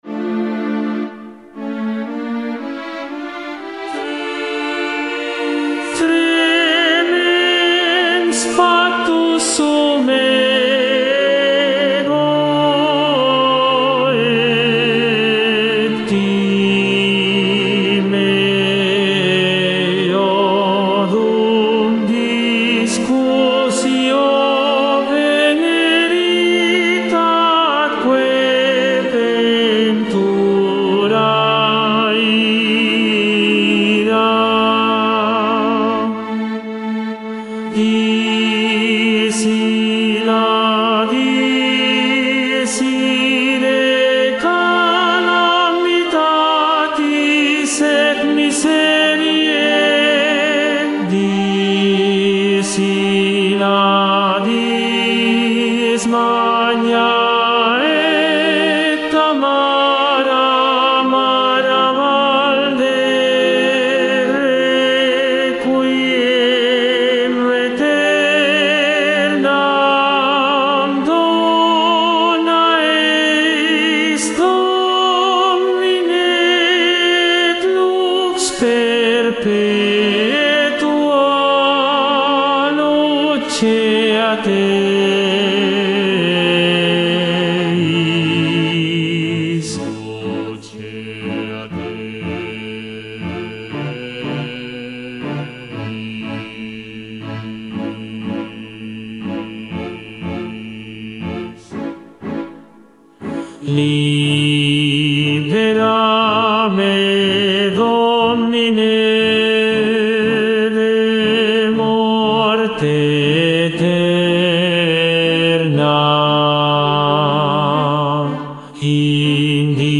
Tenor I